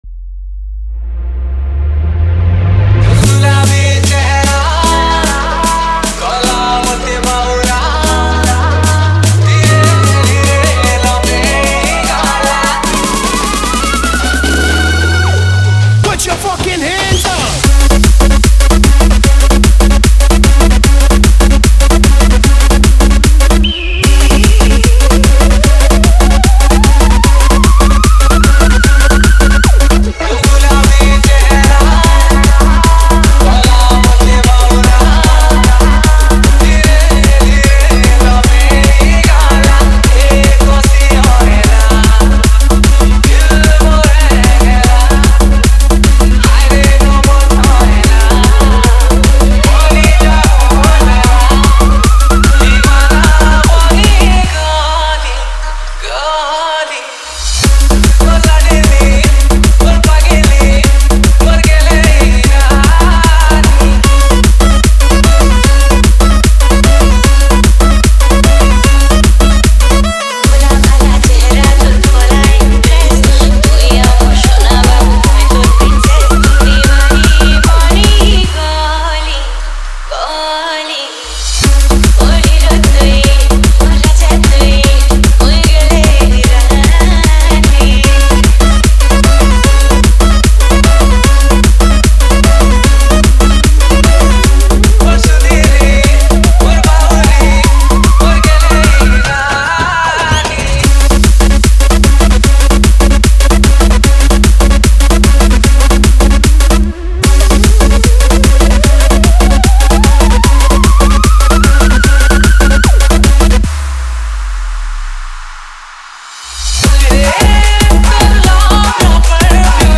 Trance Mix